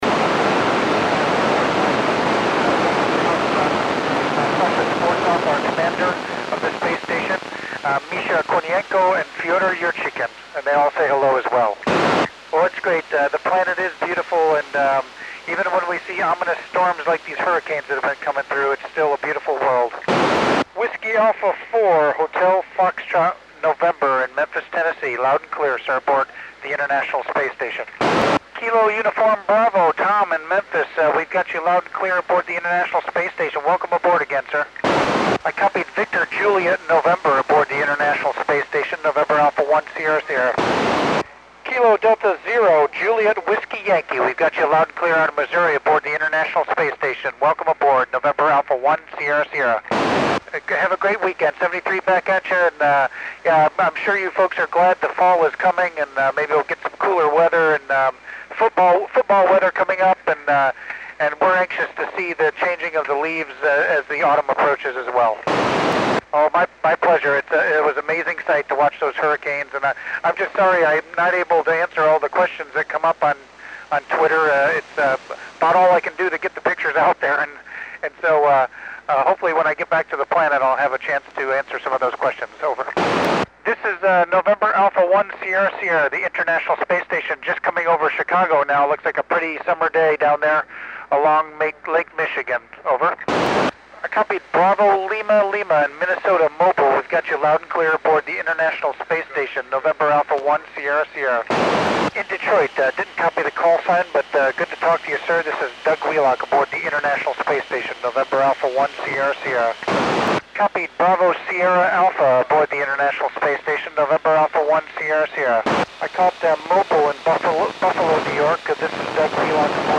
Col. Doug Wheelock (NA1SS) works U.S. stations on 04 September 2010 at 2204 UTC.